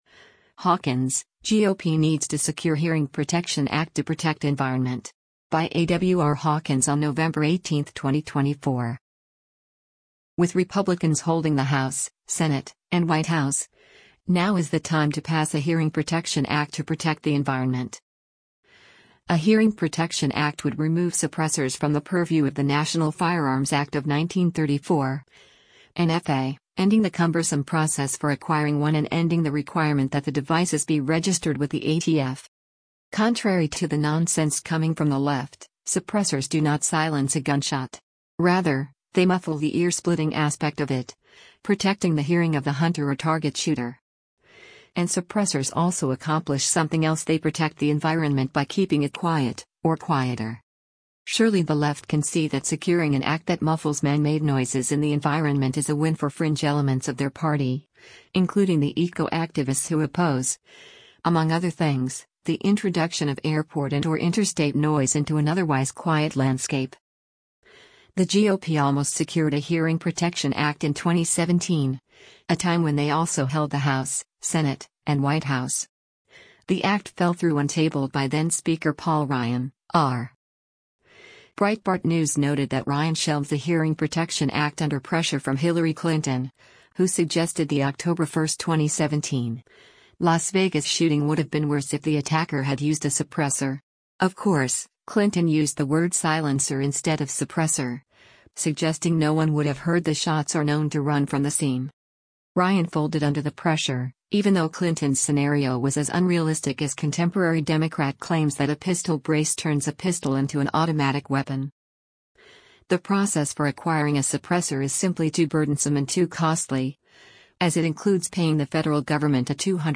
demonstrates shooting with a suppressor